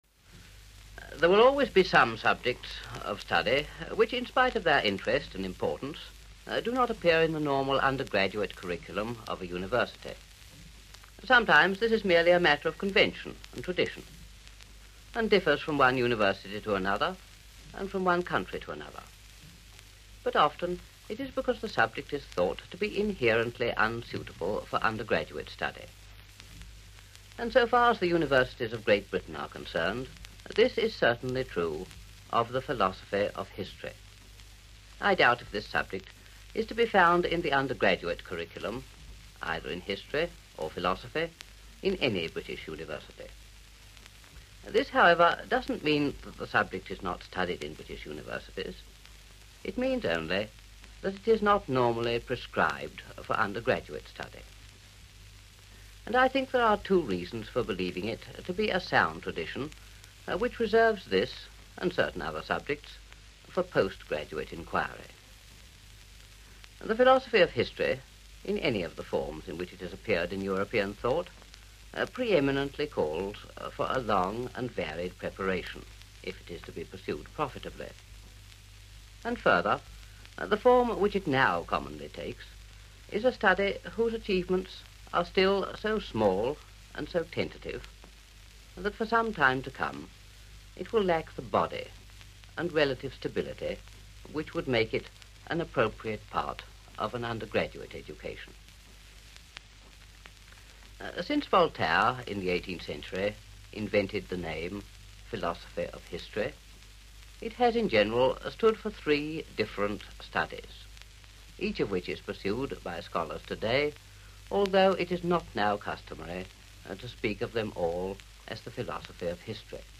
If you’ve never heard Oakeshott speak, here is a very rare 13 minute BBC recording from 1948: The University Programme: Arts – Philosophy of History .